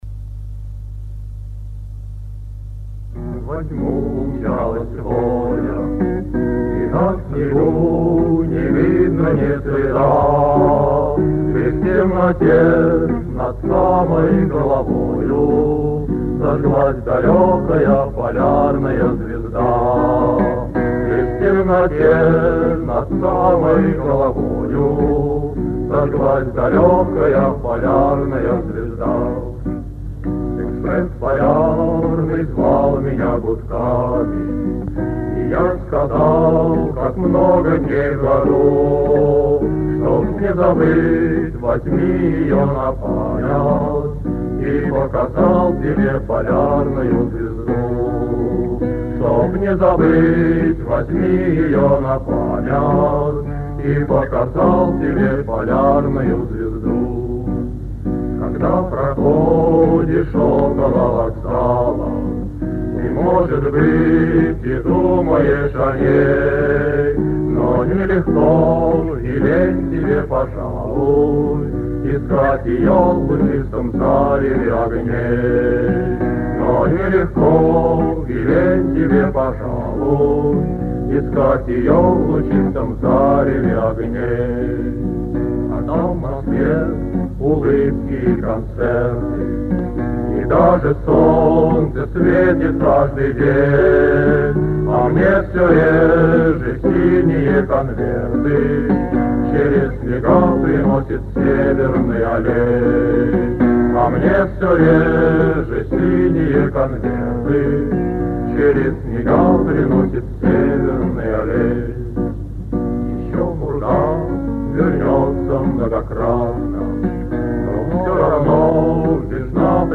ФРАГМЕНТЫ КОНЦЕРТА-КОНКУРСА 27 АПРЕЛЯ 1959 Г. ДК МЭИ
13. Мужской ансамбль МГРИ